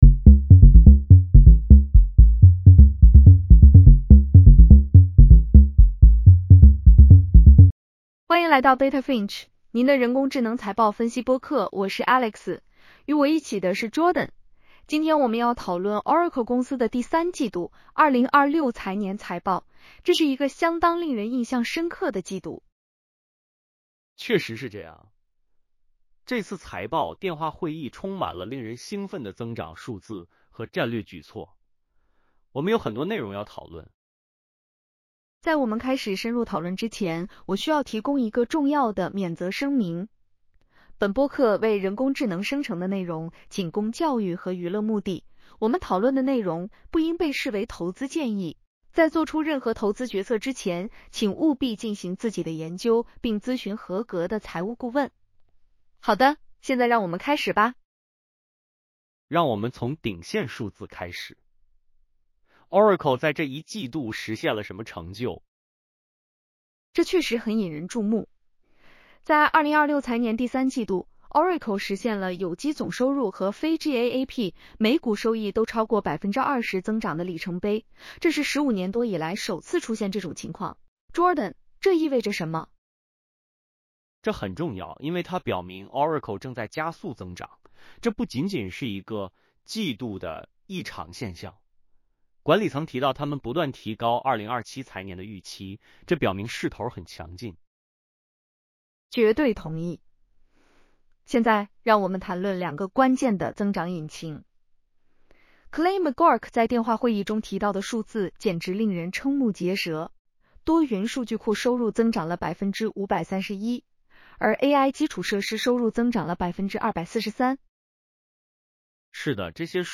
AI-powered earnings call analysis for Oracle (ORCL) Q3 2026 in CN.